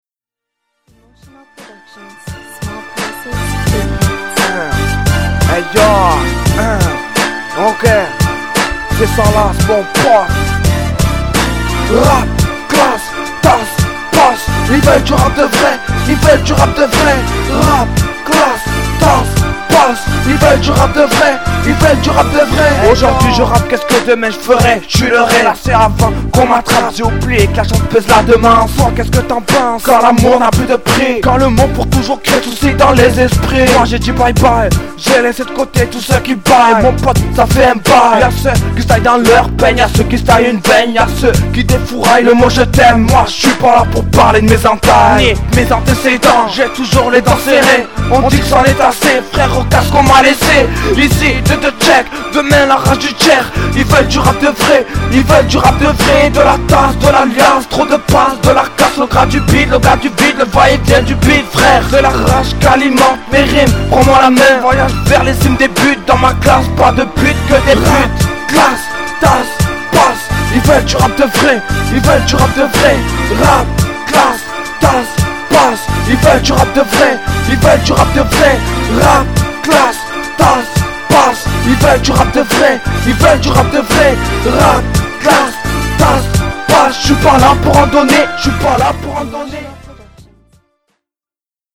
RAPPEUR DU 13 ( RAP DEPUIS 1 AN )